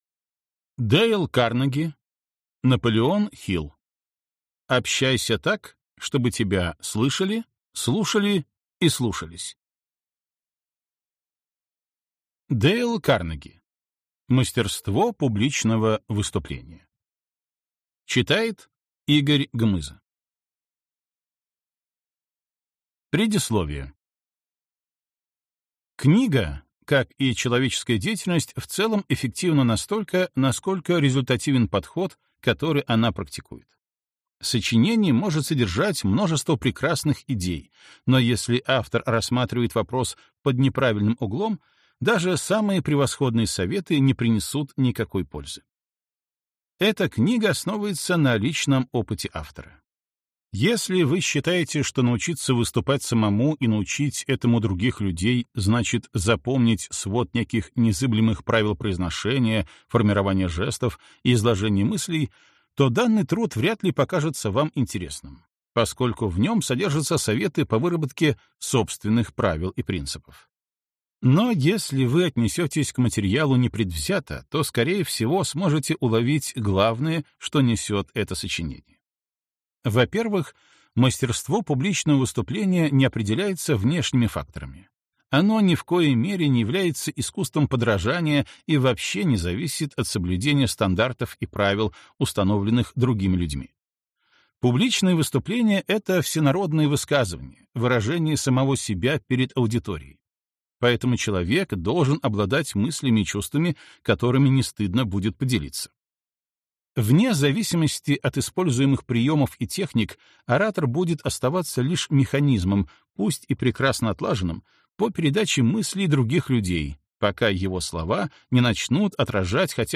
Аудиокнига Общайся так, чтобы тебя слышали, слушали и слушались!